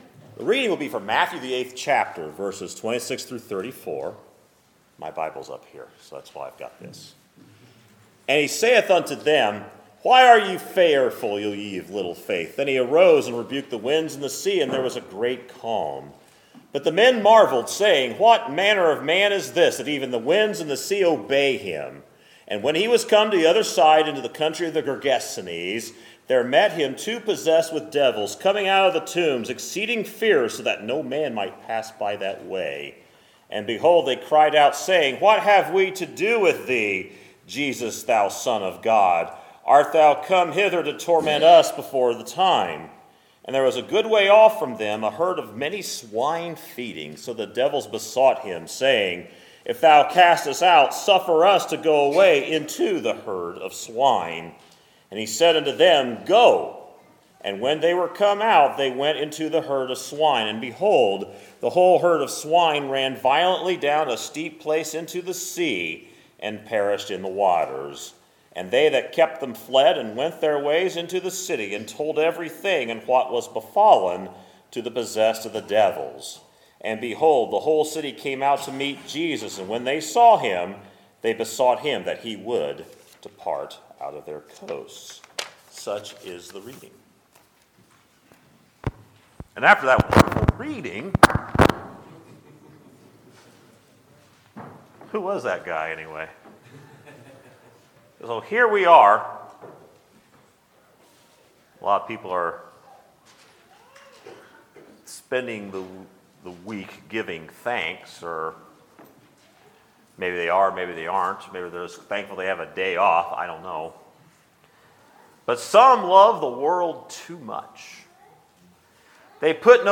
Sermons, November 26, 2017